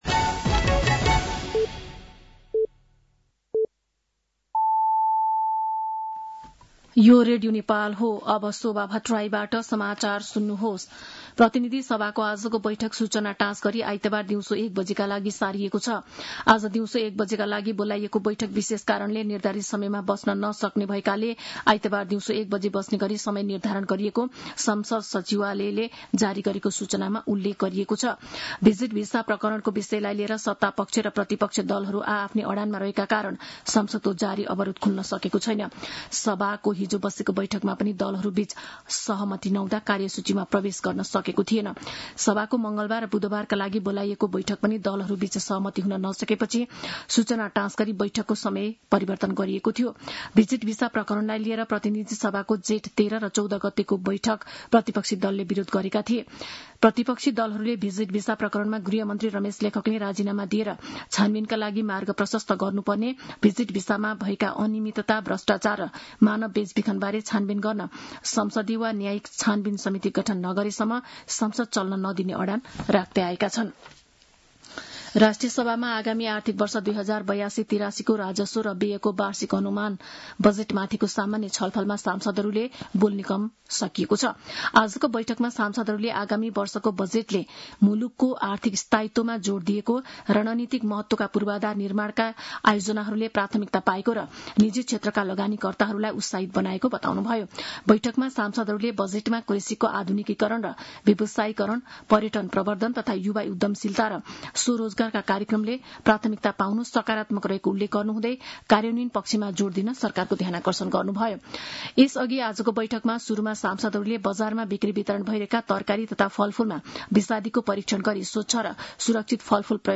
साँझ ५ बजेको नेपाली समाचार : २३ जेठ , २०८२
5-pm-nepali-news-2-23.mp3